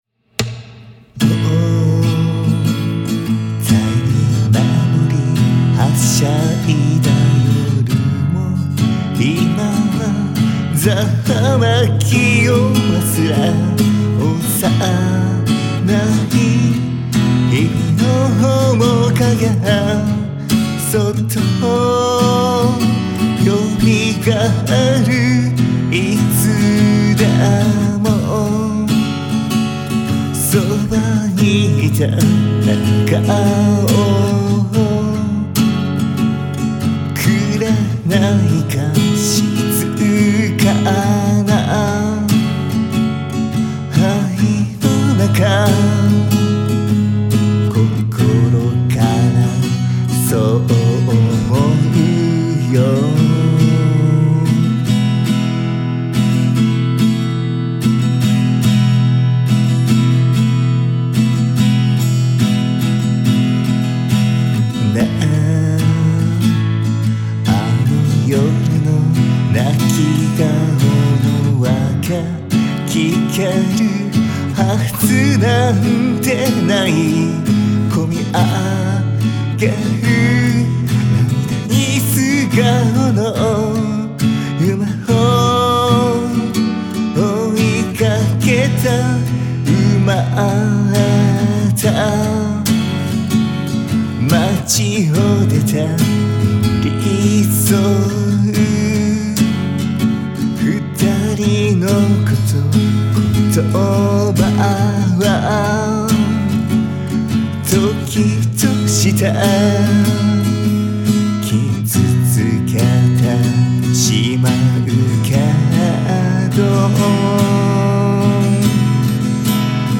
変なノイズとかないし。
・アコギ：K.yairi RF95
・マイク：RODE NT2-A
・音が前に来る。